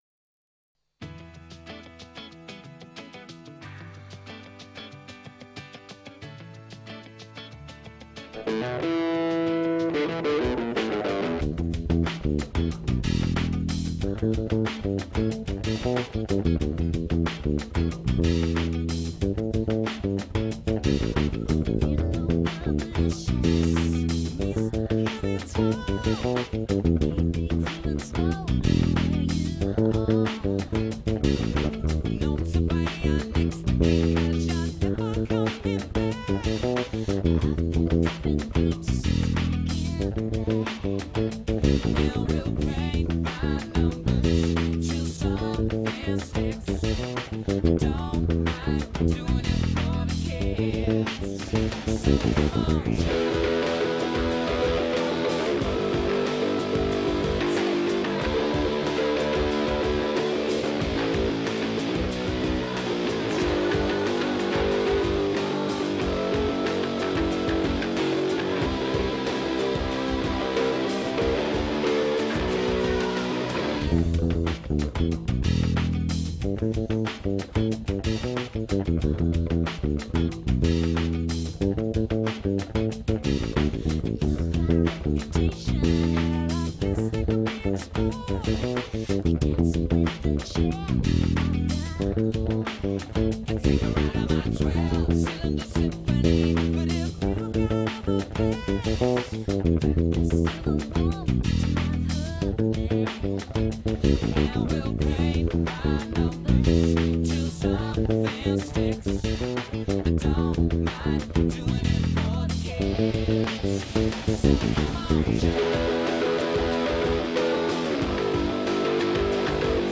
Instrumental / Bass only